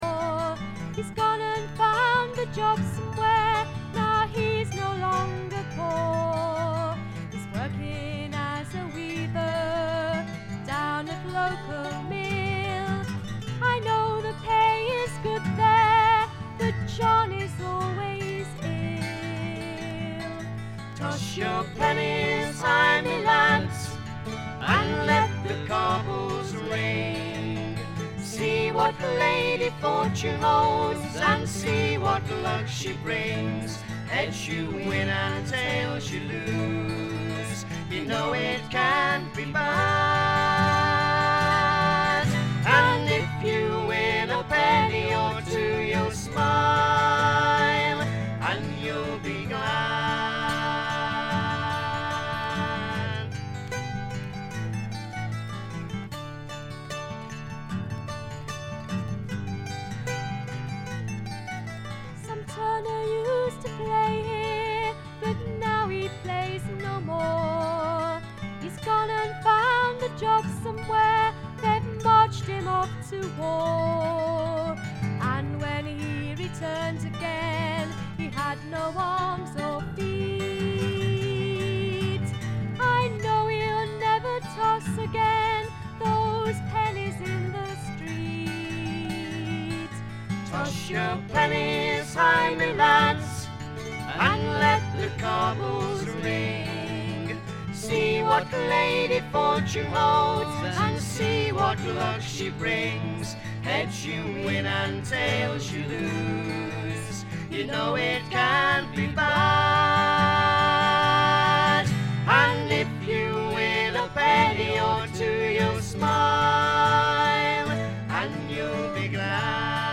バックグラウンドノイズ、チリプチ多め大きめ。プツ音少々。
すべて自作の曲をまるでトラッドのように演奏しています。
試聴曲は現品からの取り込み音源です。